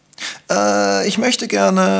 aehhh.wav